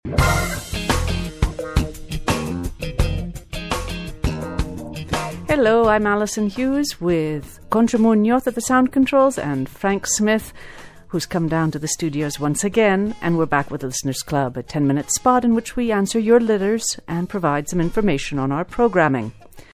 Here you will find two listening lessons based on the same snippets from an English Language Broadcast on the Spanish National Radio station.